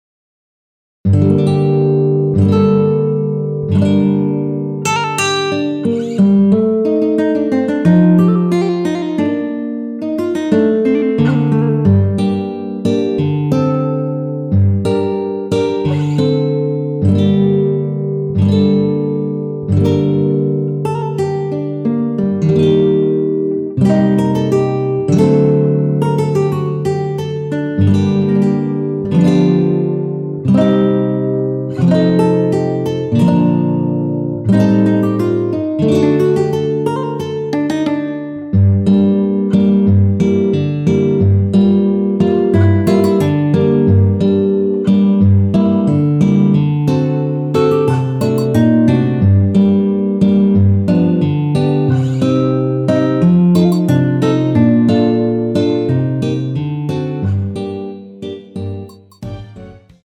원키에서(-2)내린 MR입니다.
Gm
앞부분30초, 뒷부분30초씩 편집해서 올려 드리고 있습니다.
중간에 음이 끈어지고 다시 나오는 이유는